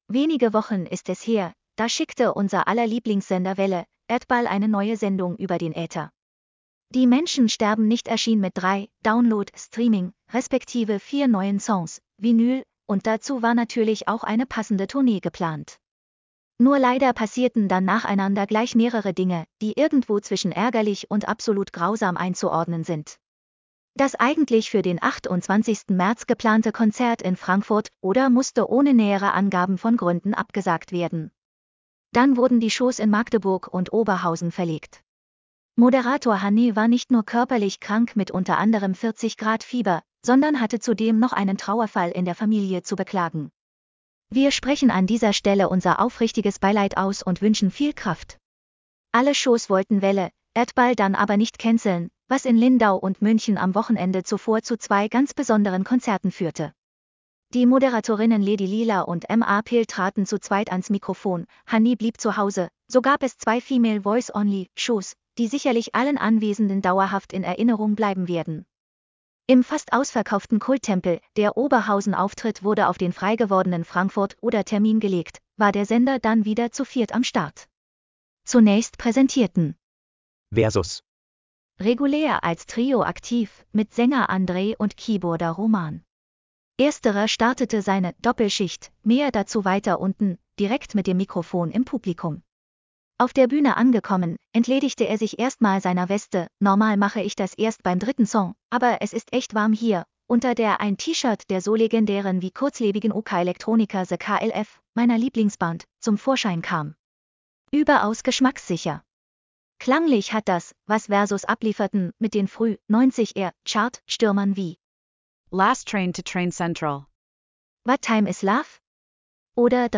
Lass Dir den Beitrag vorlesen: /wp-content/TTS/190718.mp3 Alle Shows wollten Welle:Erdball dann aber nicht canceln, was in Lindau und München am Wochenende zuvor zu zwei ganz besonderen Konzerten führte.